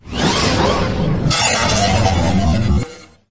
形态变换的声音效果5
描述：从ZOOM H6录音机和麦克风Oktava MK01201的现场录制的效果，然后进行处理。
Tag: 未来 托管架 无人驾驶飞机 金属制品 金属 过渡 变形 可怕 破坏 背景 游戏 黑暗 电影 上升 恐怖 开口 命中 噪声 转化 科幻 变压器 冲击 移动时 毛刺 woosh 抽象的 气氛